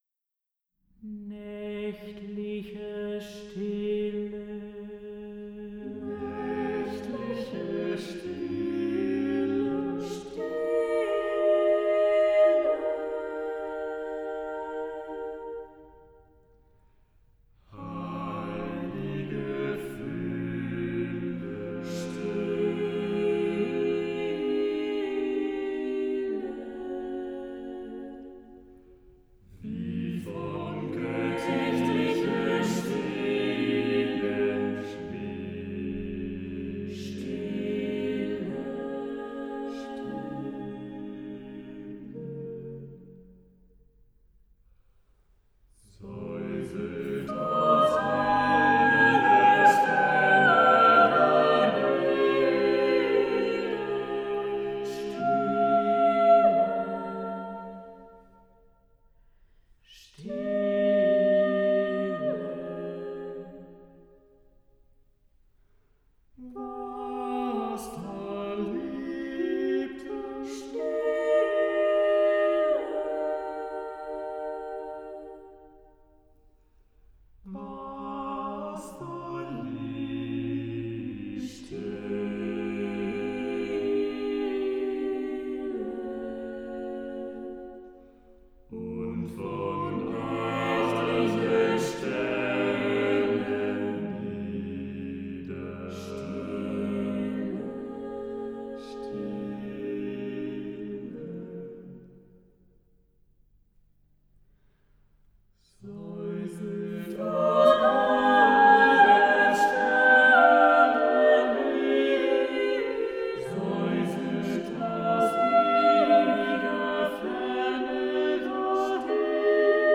SATB div. a cappella